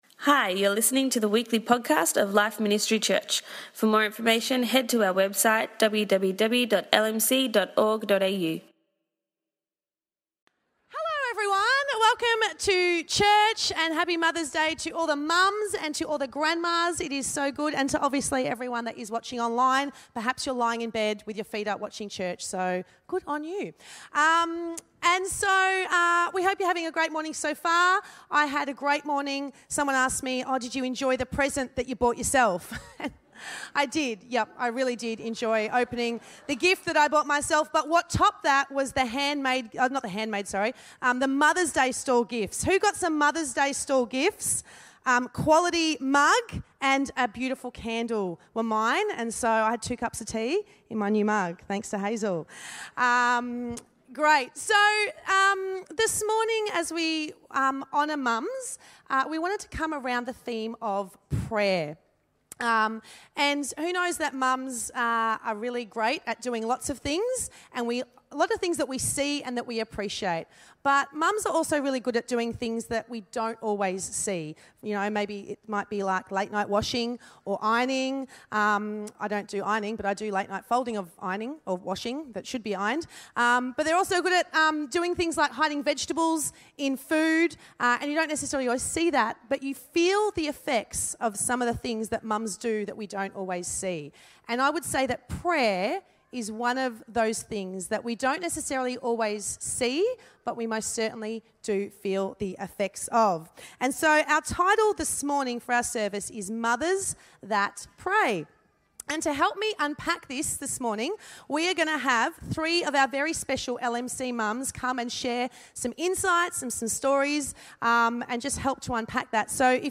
At our very special Mother's Day service we had the privilege of hearing from three mothers in our church on the subject of prayer. Sharing their wisdom and testimony of how they have seen the power of prayer outwork in their lives.